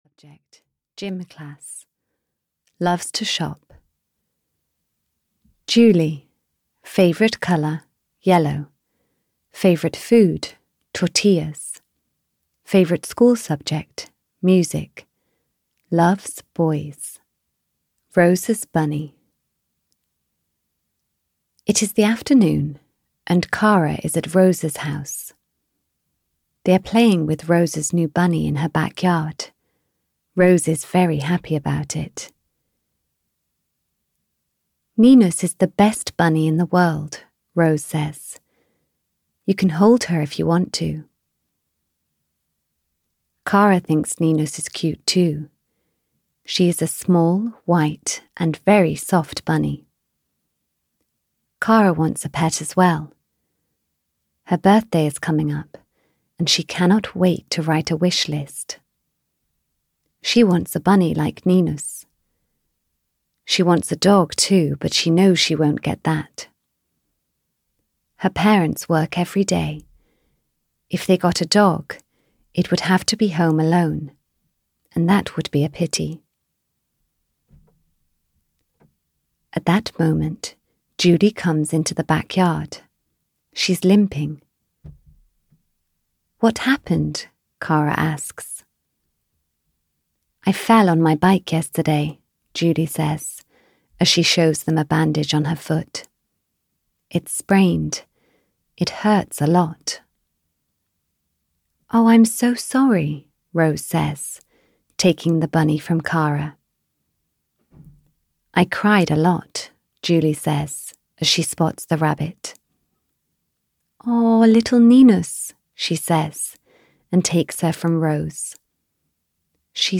K for Kara 7 - I Feel Sorry for You! (EN) audiokniha
Ukázka z knihy